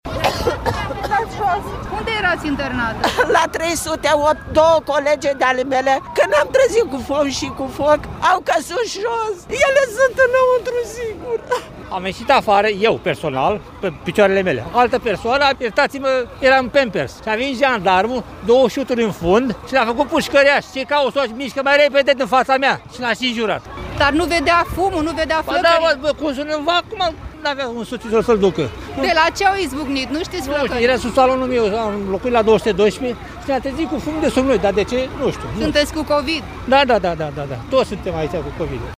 01oct-11-CORESP-CT-vox-pacienti-SCURT.mp3